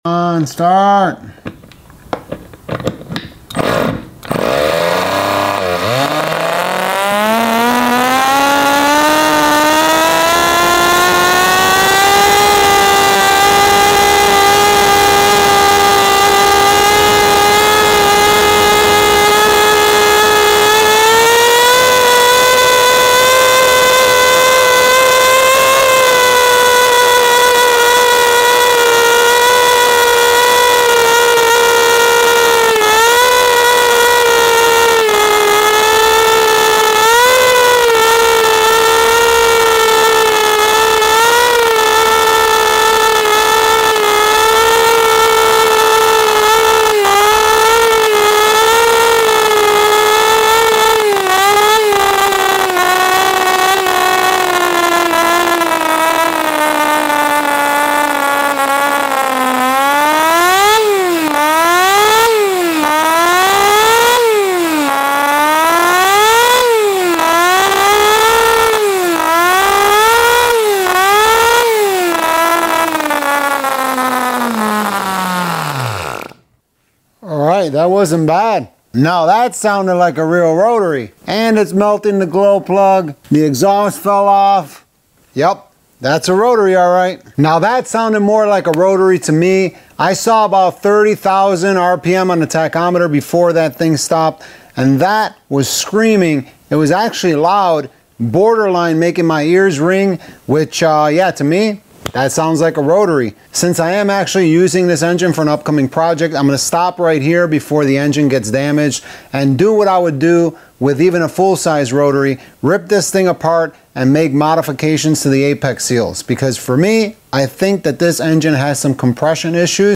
Worlds Smallest Rotary Engine (30.000 RPM)